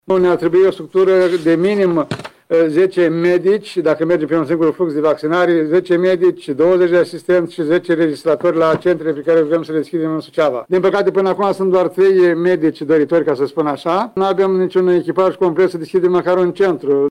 Niciun centru comunitar de vaccinare nu poate fi deschis în Suceava pentru că nu există cadre medicale interesate să lucreze acolo. Primarul orașului, Ion Lungu, spune că niciun medic nu vrea să fie coordonator de centru pentru că nu primește bani în plus: